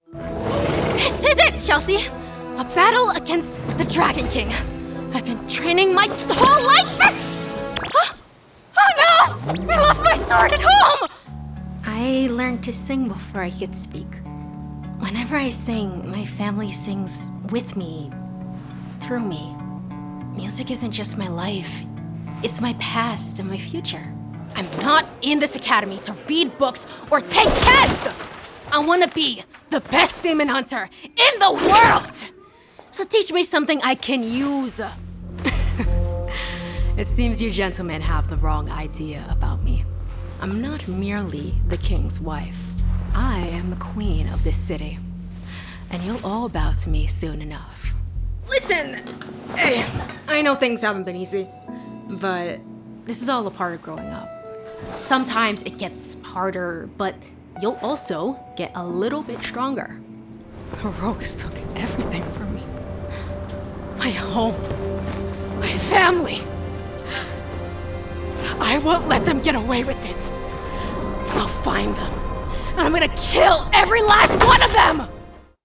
Female
Approachable, Bubbly, Conversational, Cool, Streetwise, Young, Bright, Character, Children, Confident, Energetic, Engaging, Friendly, Natural, Smooth, Versatile, Warm
General American [native], New York [native], Caribbean (Jamaican/Grenadian), RP British, African (Zulu), American Southern (Alabama, etc.)
Microphone: Neumann TLM 103, Synco D2 shotgun microphone